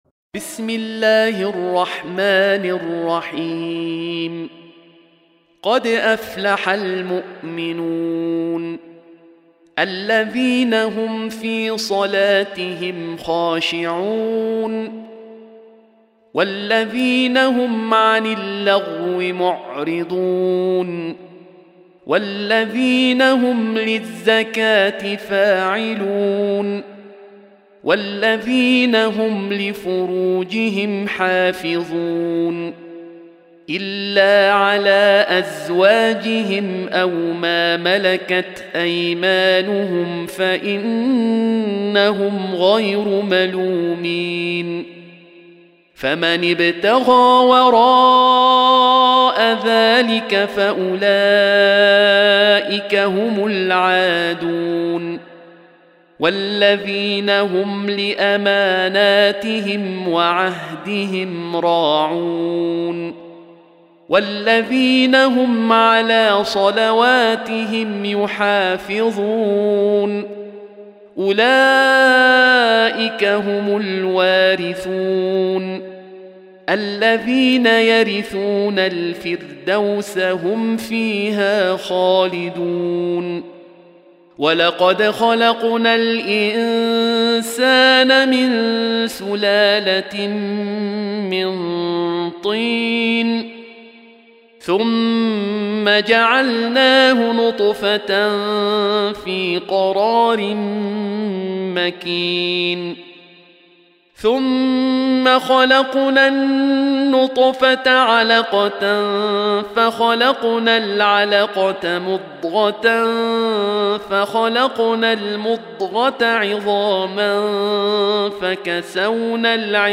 Surah Repeating تكرار السورة Download Surah حمّل السورة Reciting Murattalah Audio for 23. Surah Al-Mu'min�n سورة المؤمنون N.B *Surah Includes Al-Basmalah Reciters Sequents تتابع التلاوات Reciters Repeats تكرار التلاوات